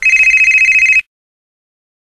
Free MP3 ringtones sound effects 2
Ringtone 21